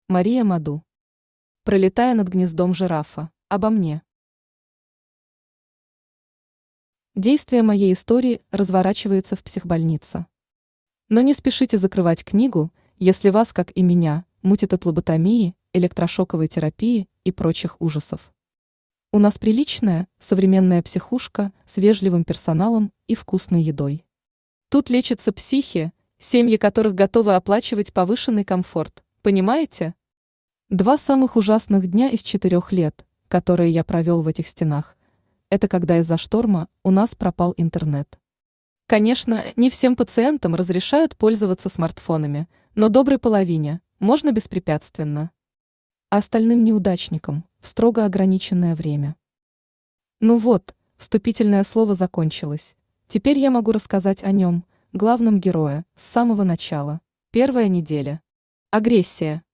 Аудиокнига Пролетая над гнездом жирафа | Библиотека аудиокниг
Aудиокнига Пролетая над гнездом жирафа Автор Мария Маду Читает аудиокнигу Авточтец ЛитРес.